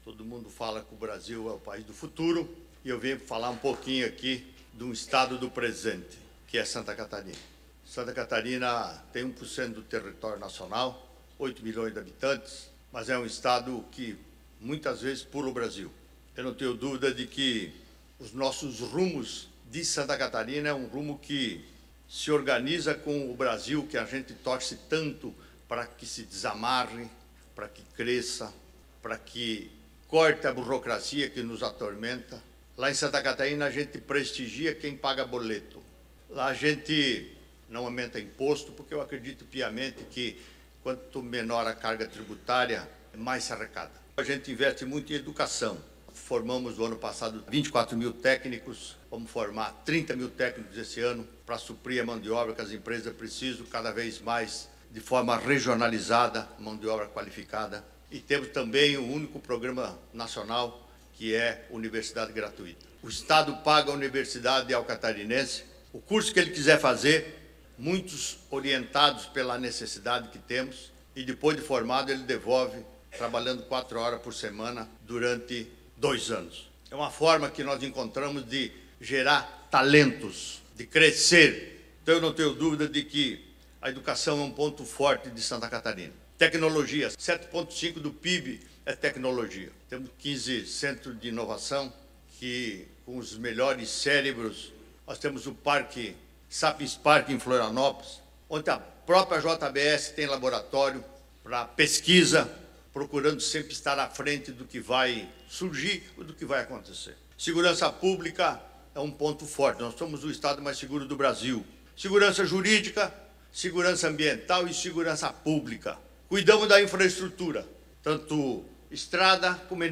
SONORA – Em Nova York, governador apresenta potenciais de SC a investidores e comitiva conhece novas aplicações de IA no Google
O governador participou do seminário Oportunidades no Brasil, promovido pelo jornal Valor Econômico, e falou a investidores, executivos e autoridades públicas, incluindo outros governadores brasileiros. Em sua fala, Jorginho Mello apresentou os diferenciais de Santa Catarina, destacando os altos índices de segurança, desenvolvimento social e econômico, além da vocação empreendedora e tecnológica do estado:
O secretário de Articulação Internacional e Projetos Estratégicos, Paulo Bornhausen, destacou a importância da visita: